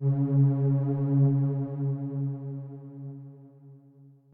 b_basspad_v127l1o4cp.ogg